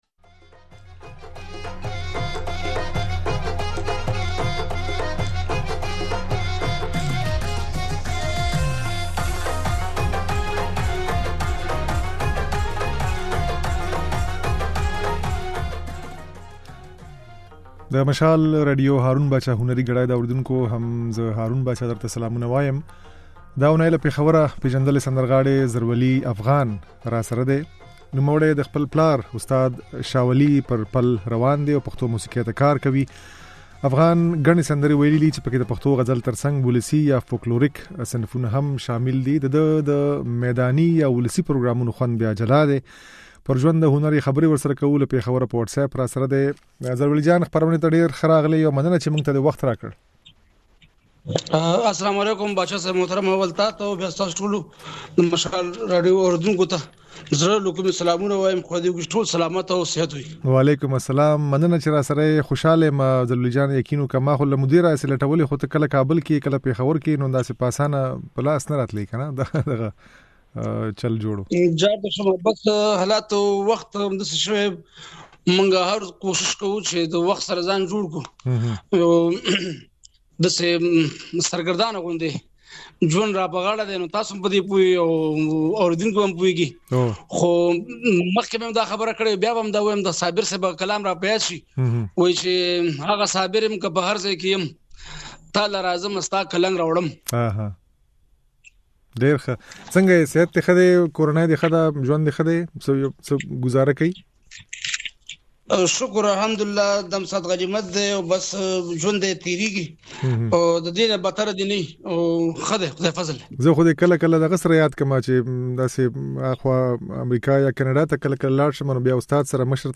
د دې اونۍ د "هارون باچا هنري ګړۍ" خپرونې مېلمه پېژندلی سندرغاړی زرولي افغان دی.
دی په خپګان وايي چې له ۳۰ کلونو موسيقۍ کولو وروسته يې طالبان نور هلته سندرو ويلو ته نه پرېږدي. په خپرونه کې د زرولي افغان خبرې او ځينې سندرې يې اورېدای شئ.